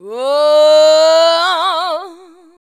WAAUUU.wav